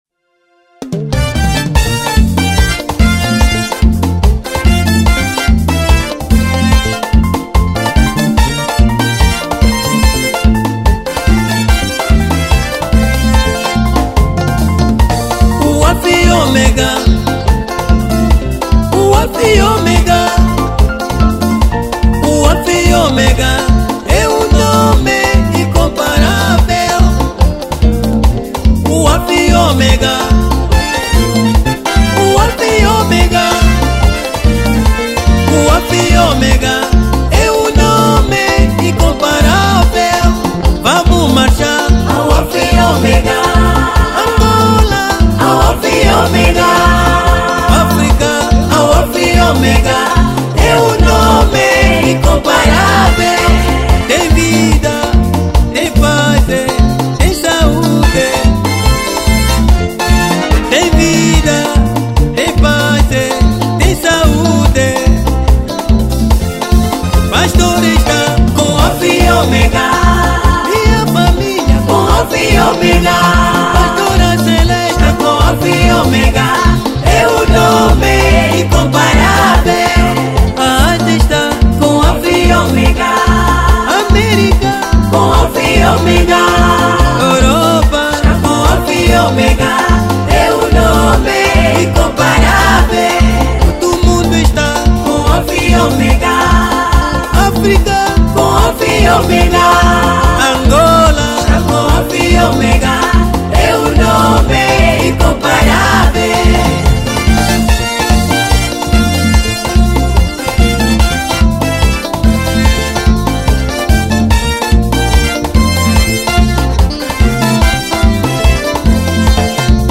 Gospel 2022